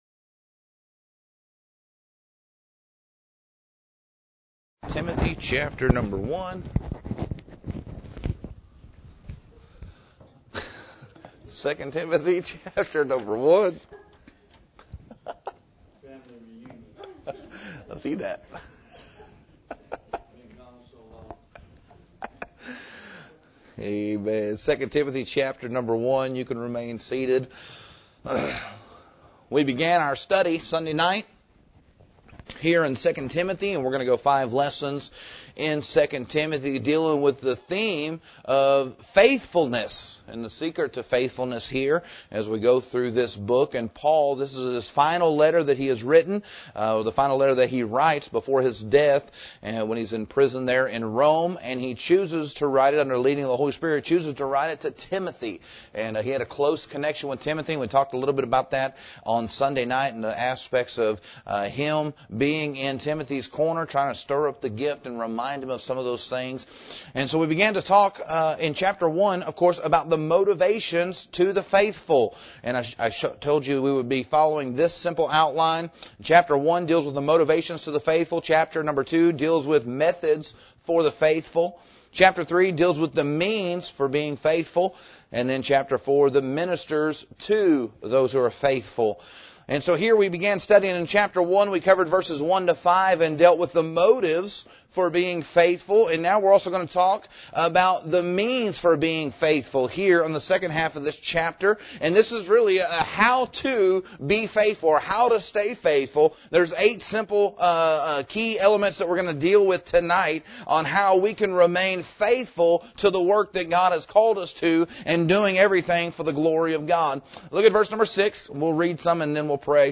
Primary Text: 2 Timothy 1:6-14, 2:3 (selected verses for sermon focus)